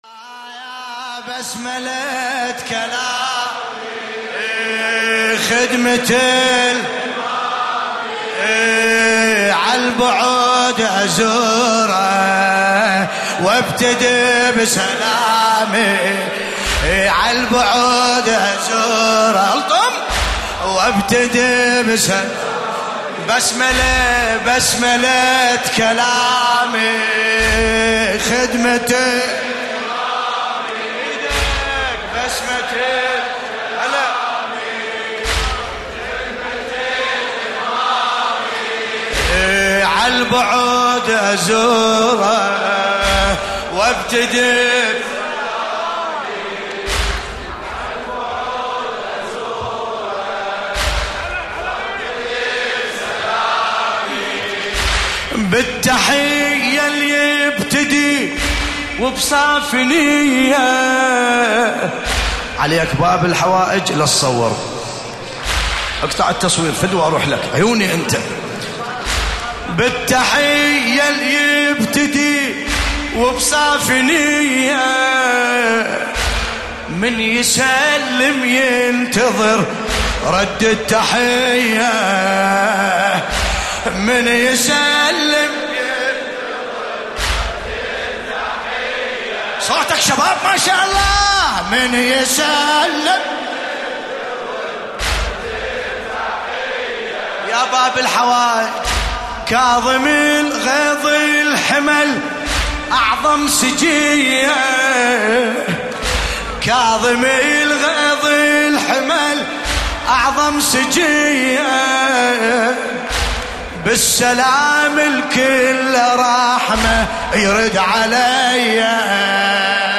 ملف صوتی بسملة كلامي بصوت باسم الكربلائي
الرادود : الحاج ملا باسم الكربلائی